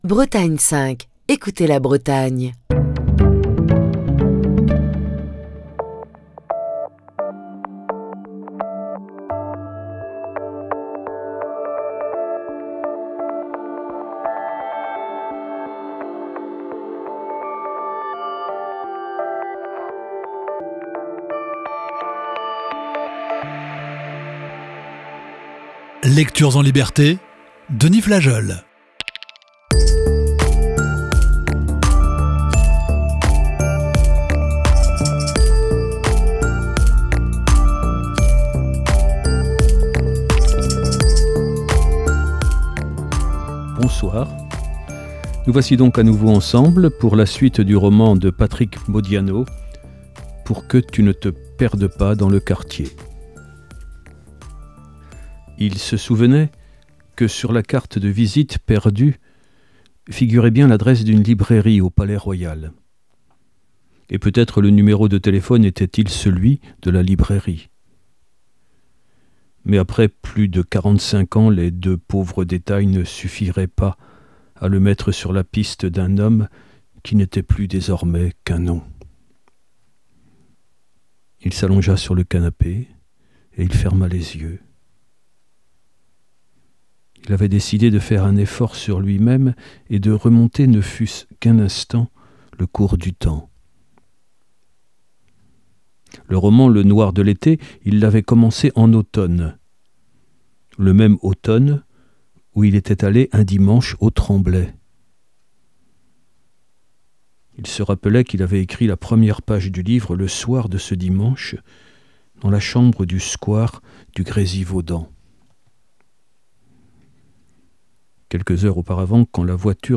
Émission du 21 février 2024.